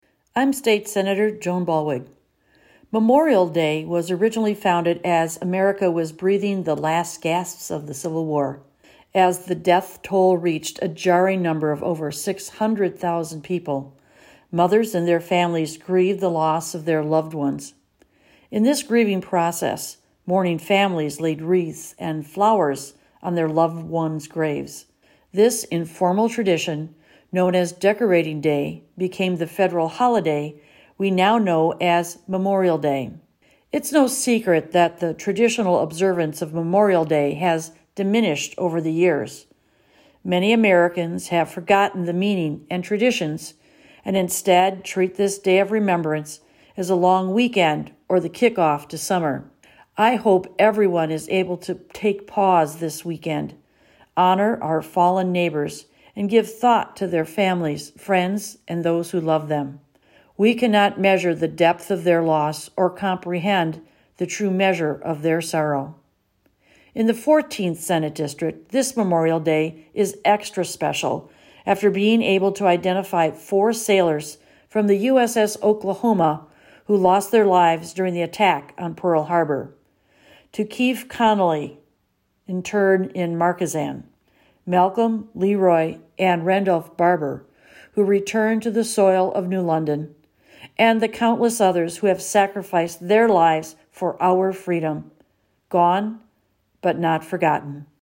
Weekly GOP Radio Address: Sen. Ballweg says many Americans have forgotten the meaning and traditions of Memorial Day - WisPolitics